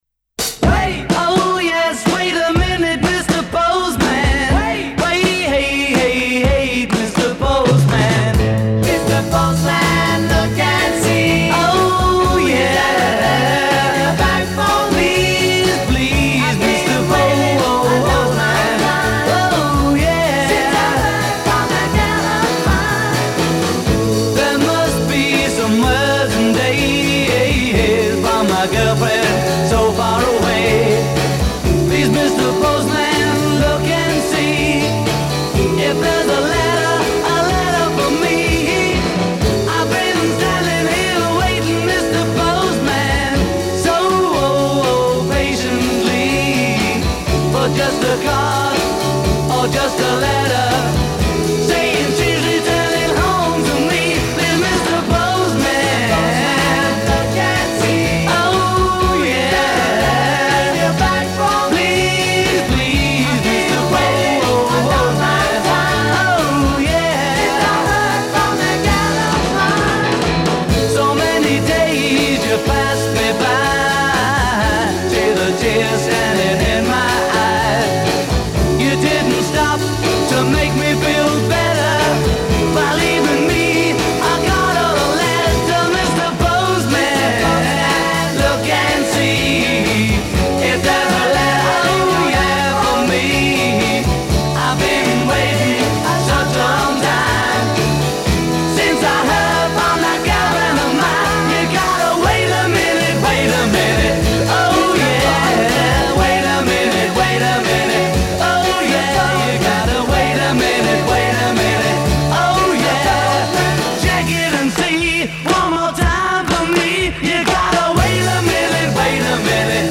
This year I feature the mono.